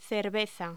Locución: Cerveza